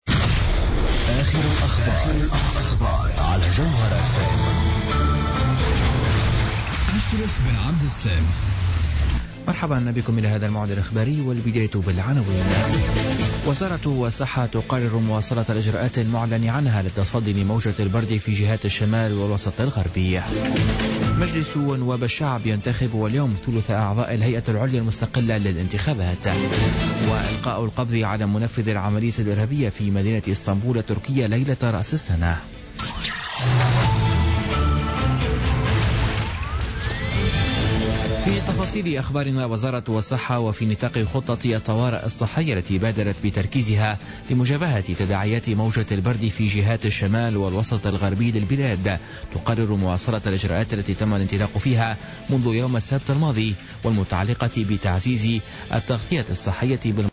نشرة أخبار منتصف الليل ليوم الثلاثاء 17 جانفي 2017